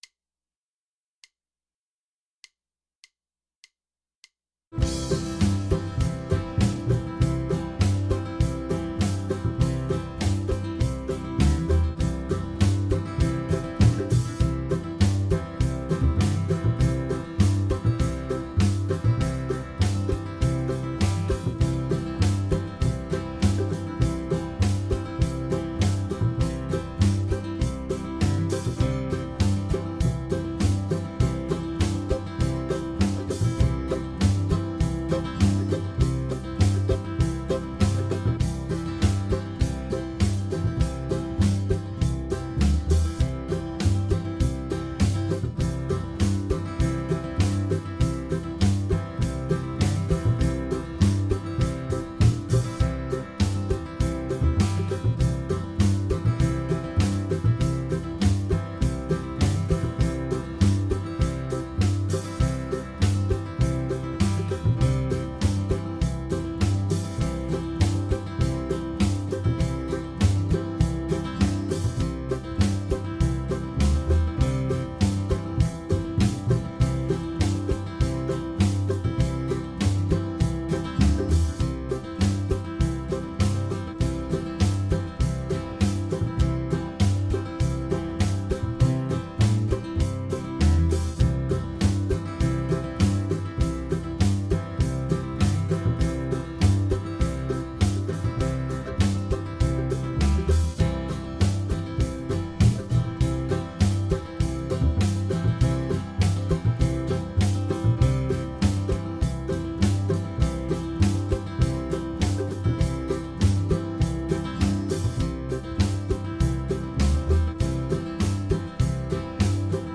Los audios de acompañamiento han sido creados con el software RealBand de una manera estándarizada como una simple progresión de acordes, para facilitar su edición, pero están hechos de forma bastante profesional y efectiva, eso sí, desde una perspectiva folk-country en casi todos ellos.
Acorde básico en DO (C)